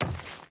Wood Chain Run 1.wav